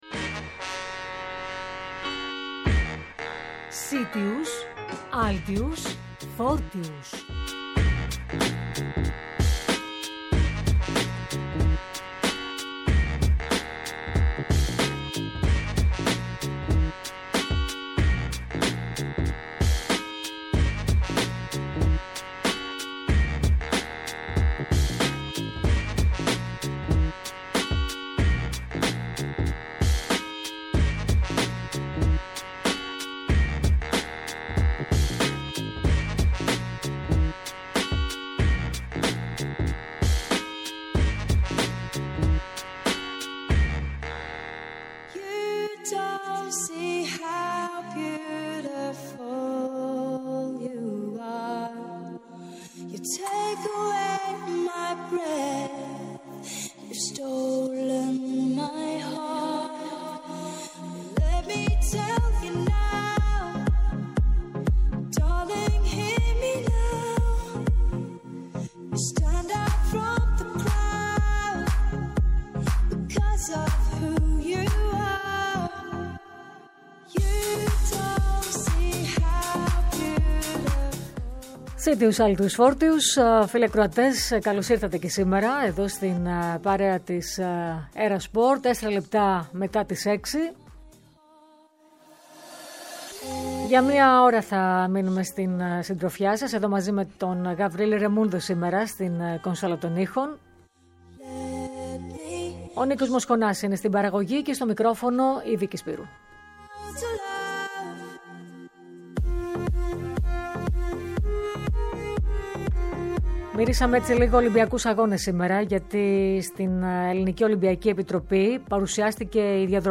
Κοντά μας σήμερα ήταν: ο χρυσός ολυμπιονίκης της κωπηλασίας, στο Τόκυο, Στέφανος Ντούσκος, ο οποίος θα είναι και ο πρώτος λαμπαδηδρόμος, στην λαμπαδηδρομία, “ΠΑΡΙΣΙ 2024”!!Μας μίλησε για την τιμητική αυτή διάκριση, αλλά και για την προετοιμασία του ενόψει των Ολυμπιακών του Παρισιού.